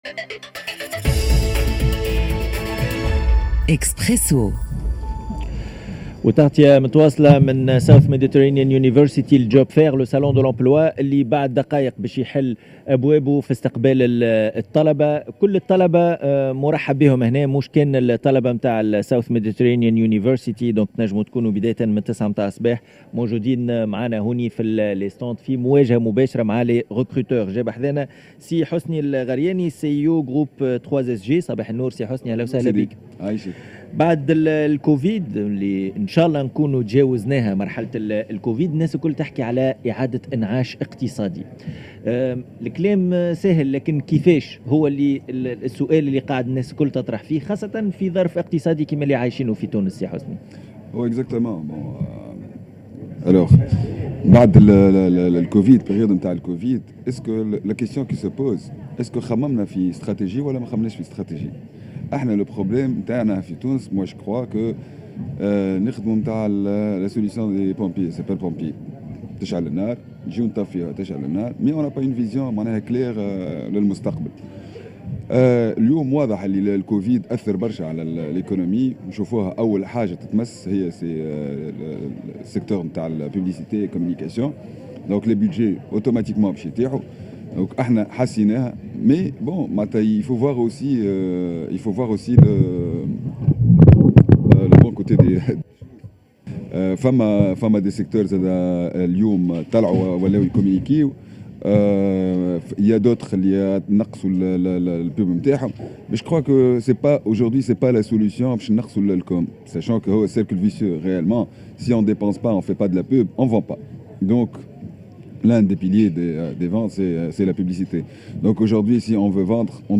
La relance économique poste COVID-19 Salon de L'Emploi SMU